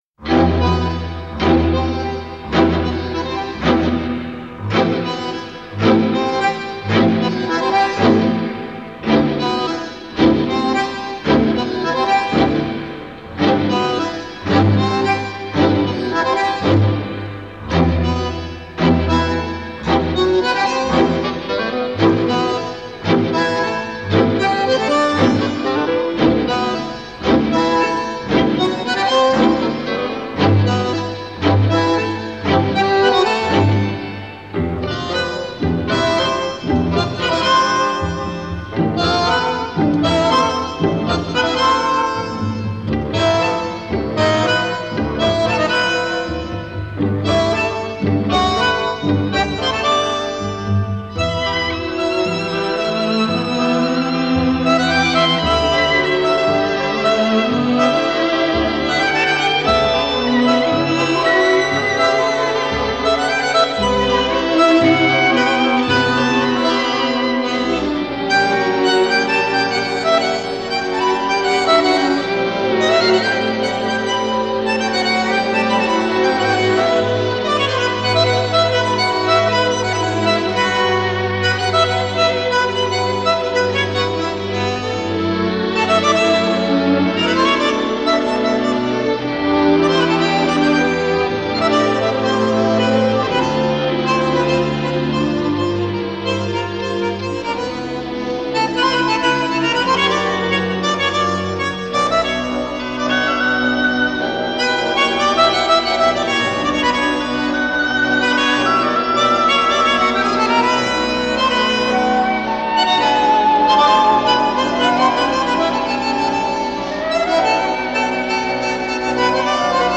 Genre: Tango Nuevo